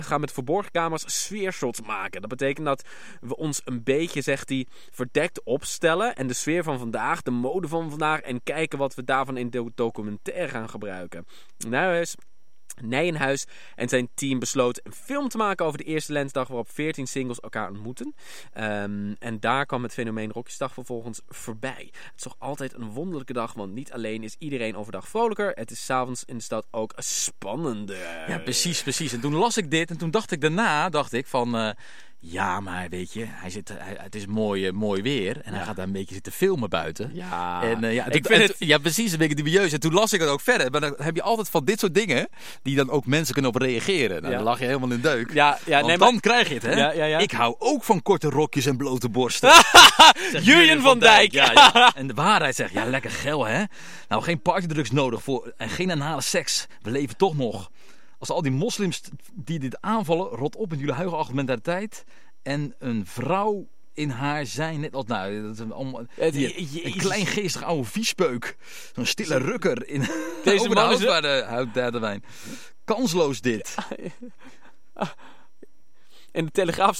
lezen het Belangrijke Nieuws voor